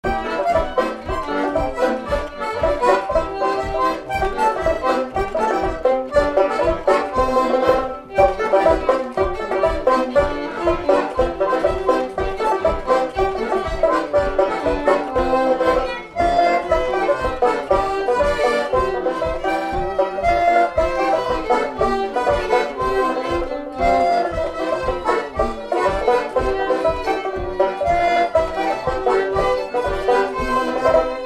Séga
Résumé Instrumental Fonction d'après l'analyste danse : séga
Catégorie Pièce musicale inédite